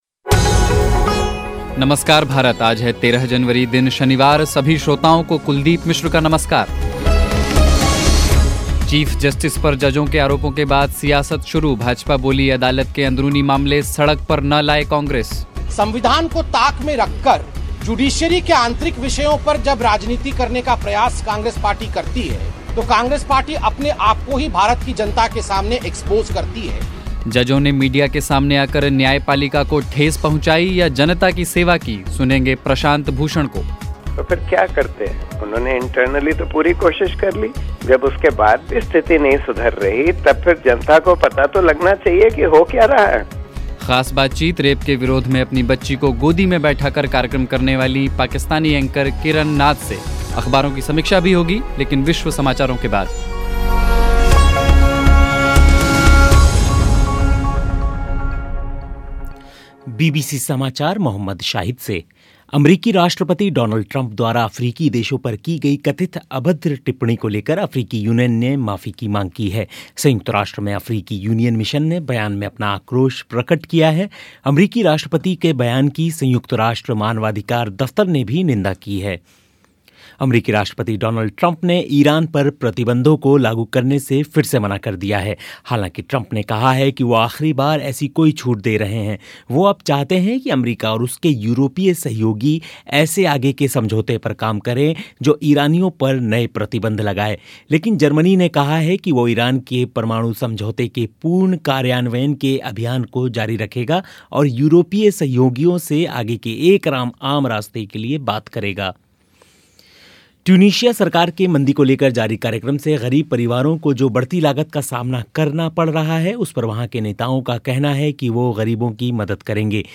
ख़ास बातचीत